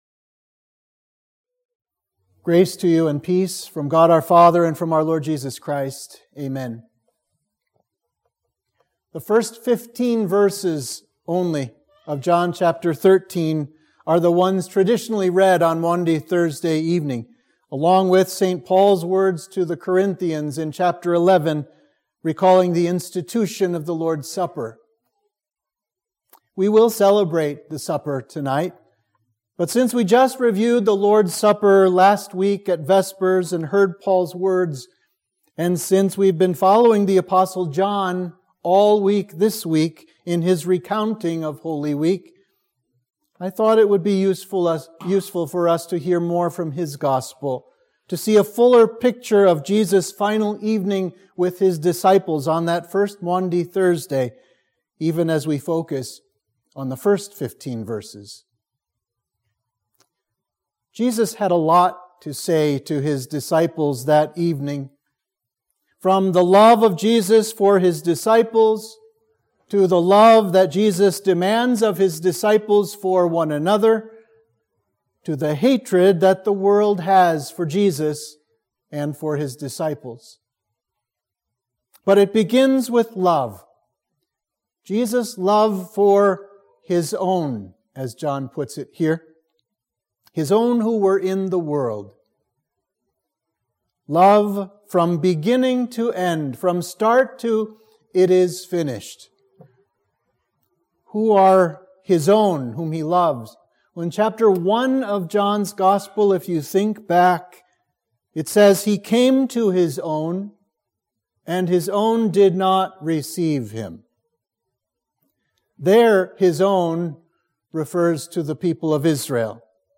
Sermon for Maundy Thursday